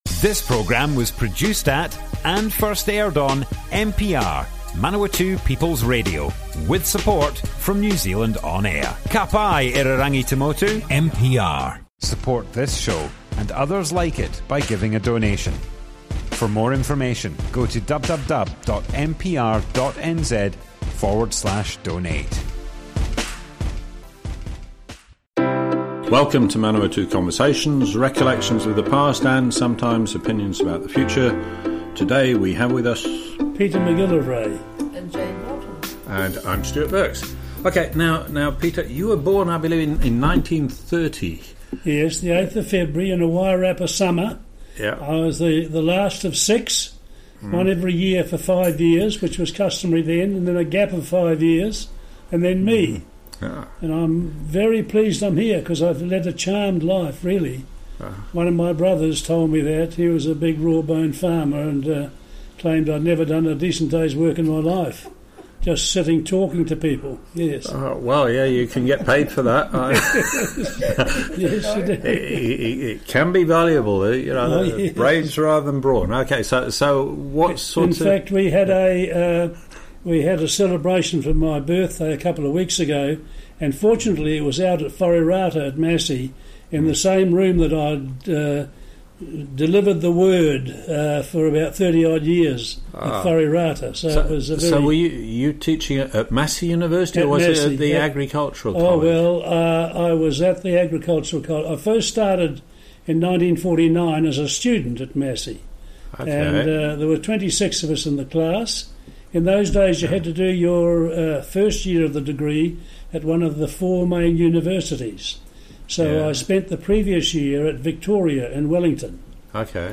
Manawatu Conversations More Info → Description Broadcast on Manawatu People's Radio, 7th April 2020.
oral history